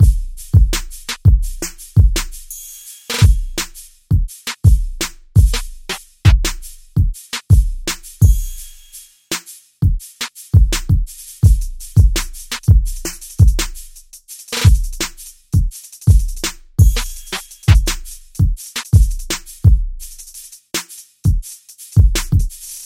Tag: SoundEffect中 效果